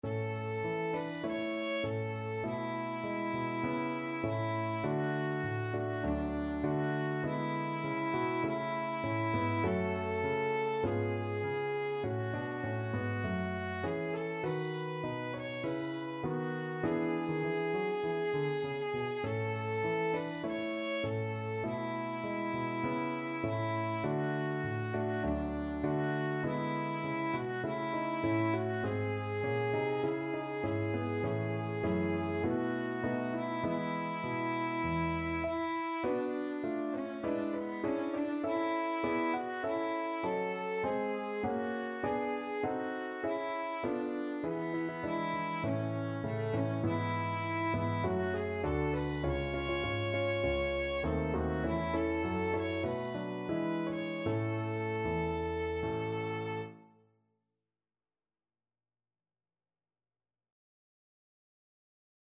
Violin
Traditional Music of unknown author.
A major (Sounding Pitch) (View more A major Music for Violin )
4/4 (View more 4/4 Music)
irish_nat_anth_VLN.mp3